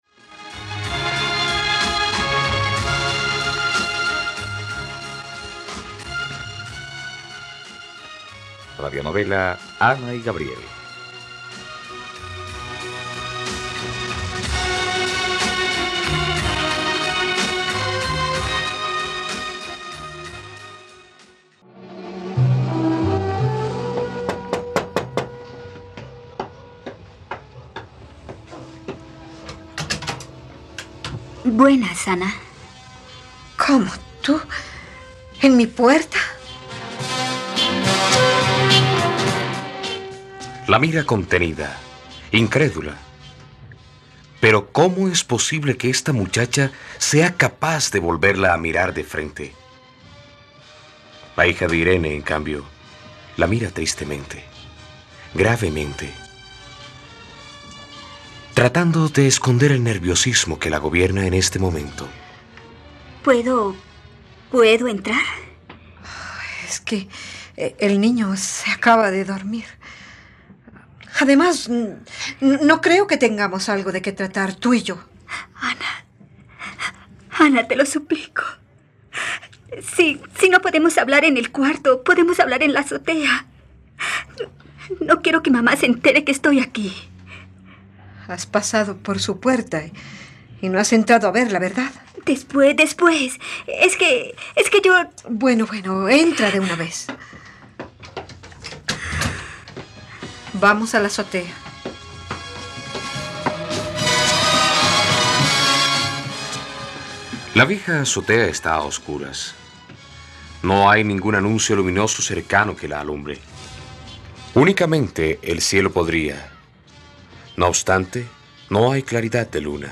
..Radionovela. Escucha ahora el capítulo 29 de la historia de amor de Ana y Gabriel en la plataforma de streaming de los colombianos: RTVCPlay.